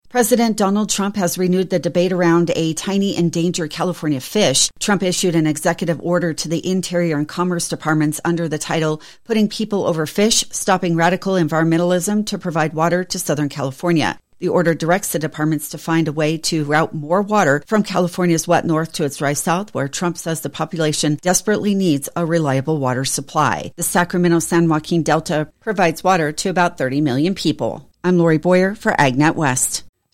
An audio report